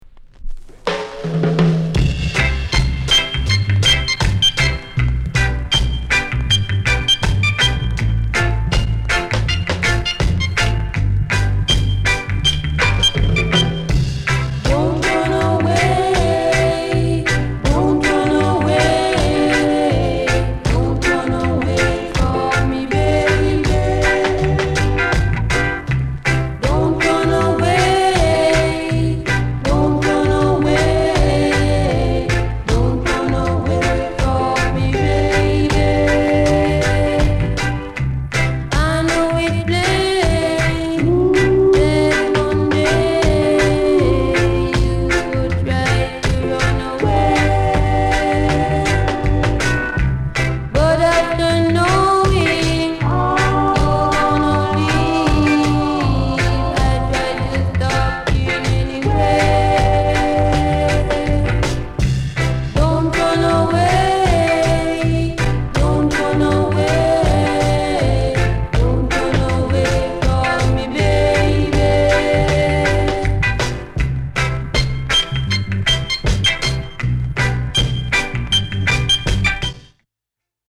GREAT ROCKSTEADY